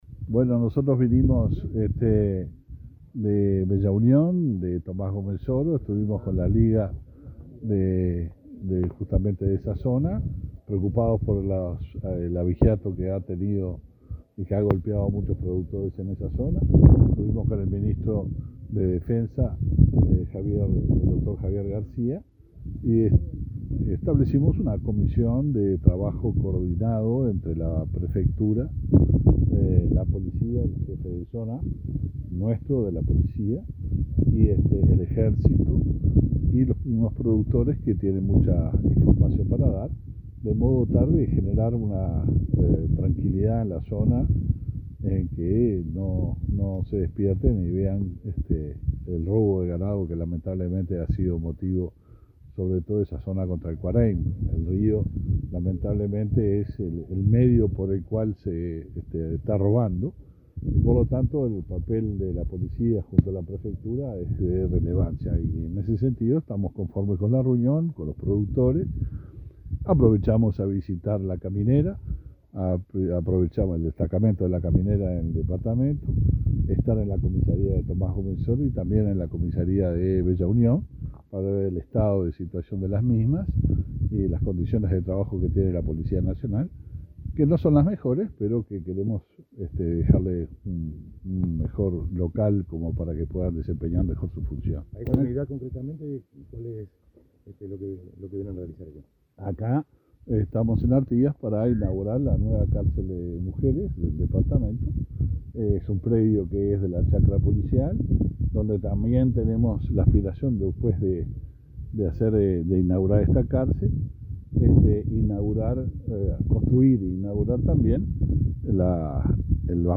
Declaraciones a la prensa del ministro del Interior, Luis Alberto Heber, tras reunión con vecinos de Bella Unión
El ministro del Interior, Luis Alberto Heber, junto al ministro de Defensa Nacional, Javier García, mantuvo una reunión con más de 100 vecinos de Bella Unión y Tomás Gomensoro para tratar sobre abigeato en esa zona del país. Tras el encuentro, Heber efectuó declaraciones a la prensa.